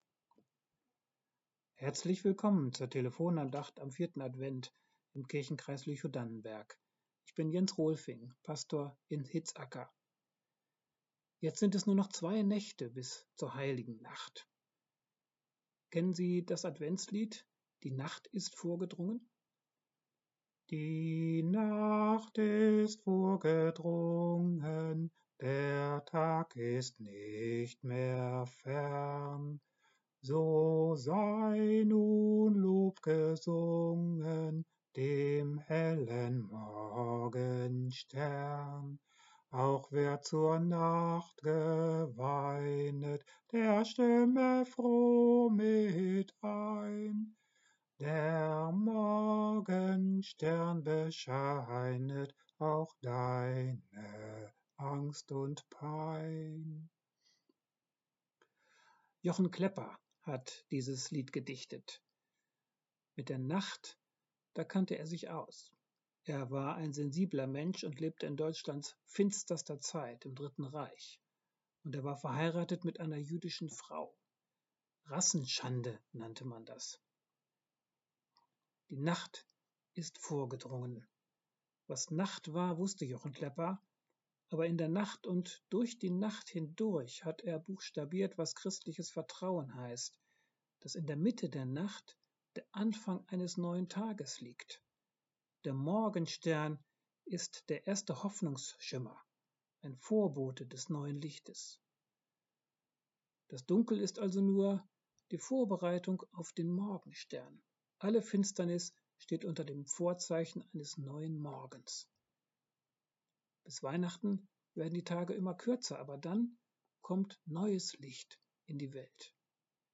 Die Nacht ist vorgedrungen ~ Telefon-Andachten des ev.-luth.